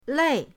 lei4.mp3